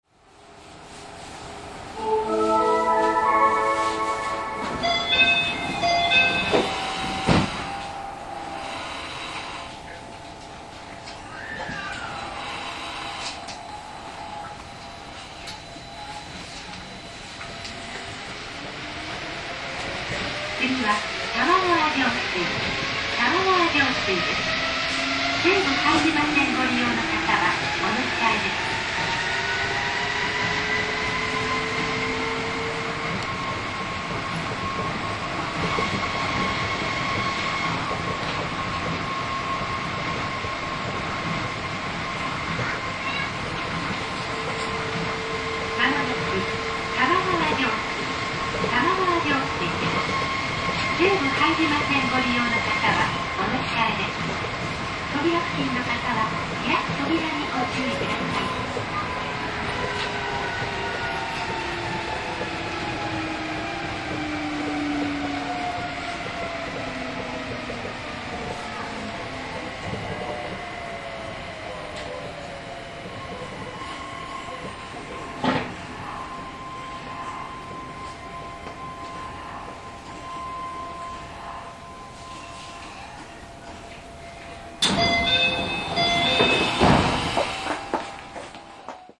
走行音
TM02 1000系 桜街道→玉川上水 1:29 10/10 上の続きです。